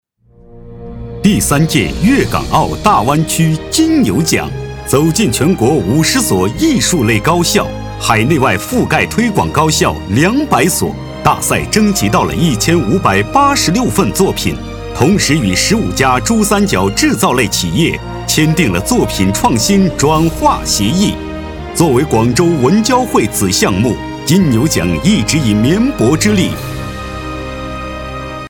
男79号-晚会颁奖配音-大气激情-金牛奖开场